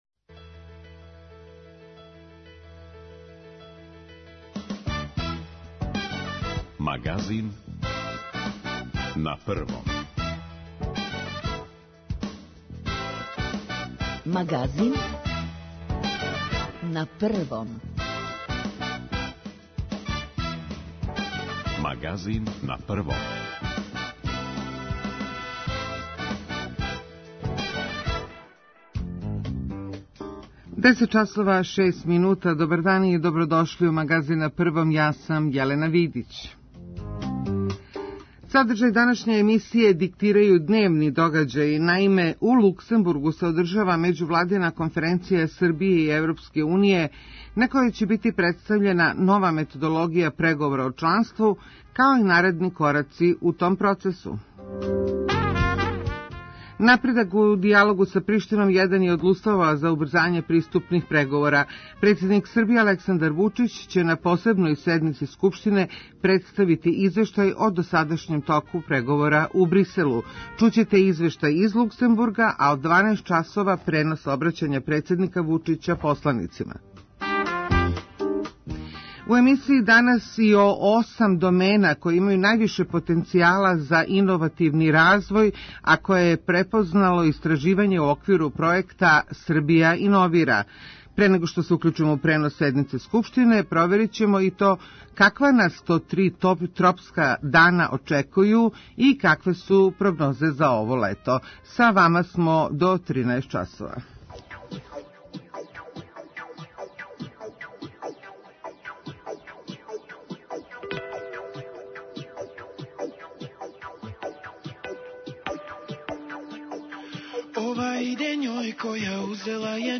Чућете извештај из Луксембурга а од 12 часова пренос обраћања председника Вучића посланицима.